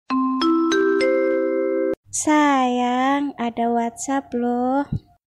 Nada notifikasi Sayang ada WhatsApp lho
Kategori: Nada dering
nada-notifikasi-sayang-ada-whatsapp-lho-id-www_tiengdong_com.mp3